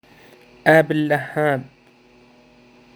آب اللهاب App El-lahab phrase in Syrian Flaming August جملة مبالغة للإشارة الى الحرارة العالية في شهر الثامن آب/أغسطس